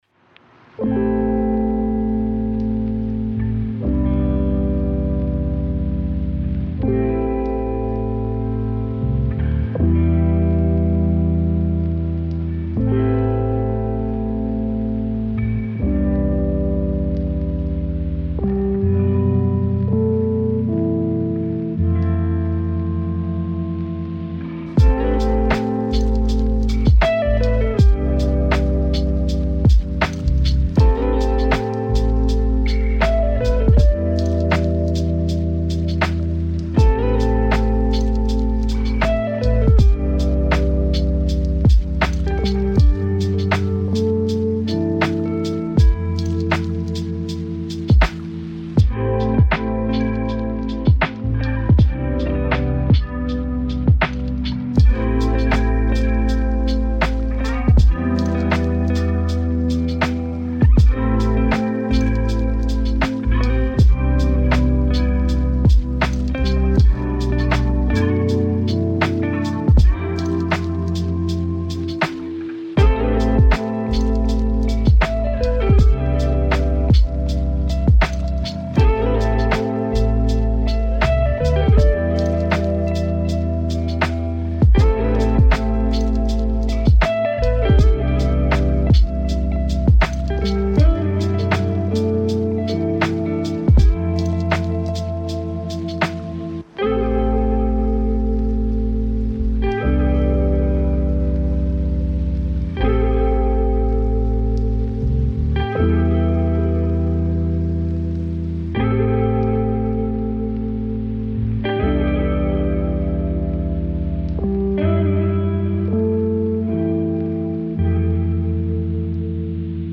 Session 1h : Ambiance Café Réelle